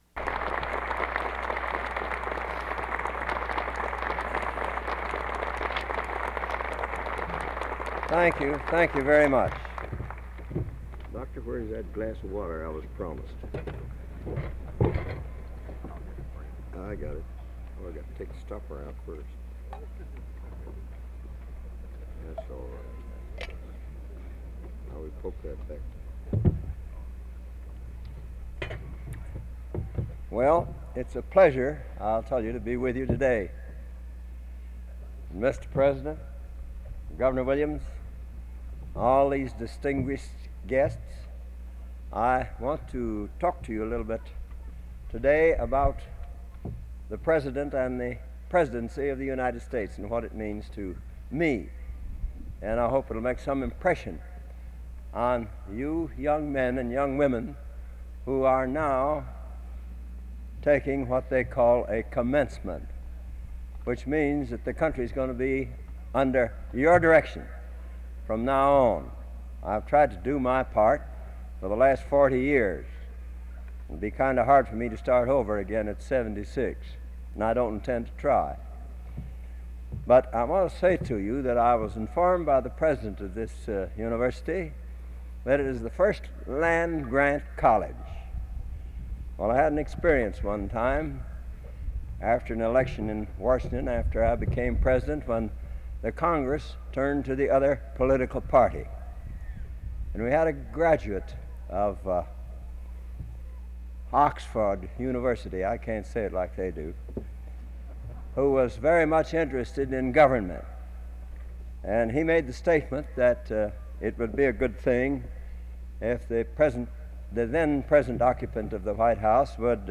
Commencement Address: Spring 1960
Commencement Address: Spring 1960 Back Creator: WKAR Subjects: People, Students, Presidents, Commencements Description: The Honorable Harry S. Truman, thirty-third President of the United States, gives the address speech at MSU's 100th annual commencement in Spartan Stadium.
Original Format: Open reel audio tape